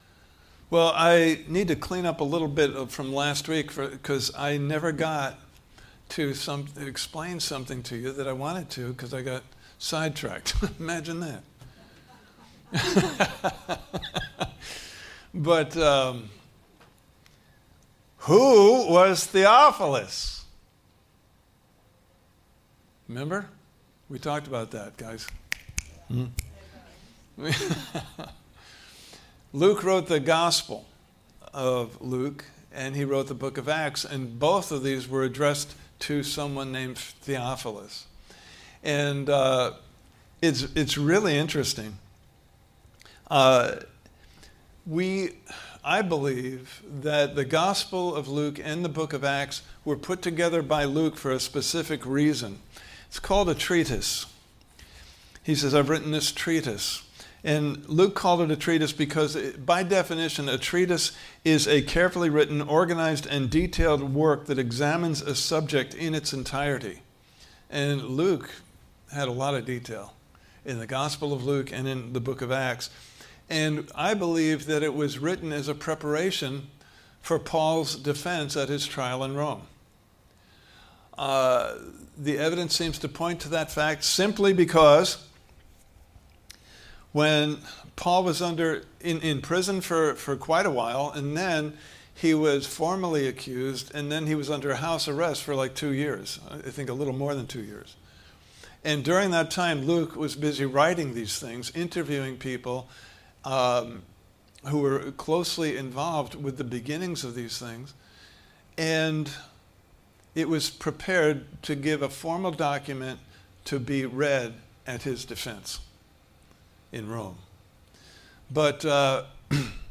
Service Type: Sunday Morning Service « Part 1: The Spirit of Resurrection Power!